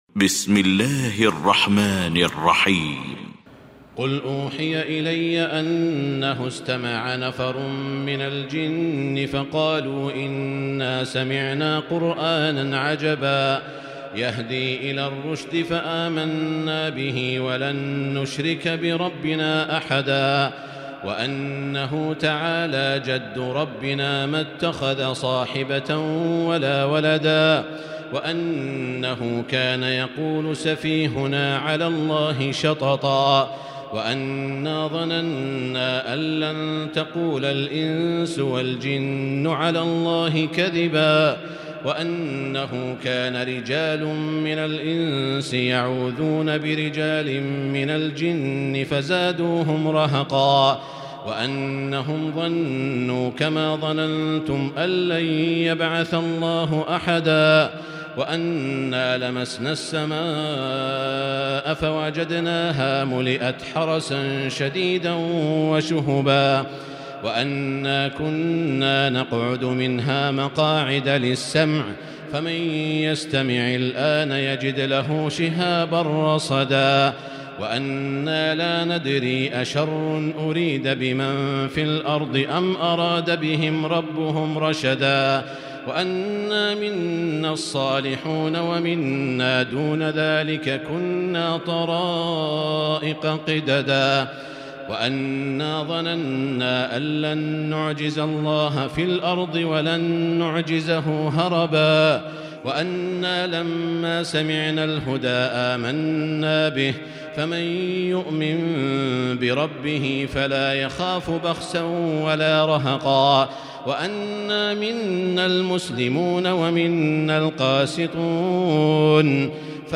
المكان: المسجد الحرام الشيخ: سعود الشريم سعود الشريم الجن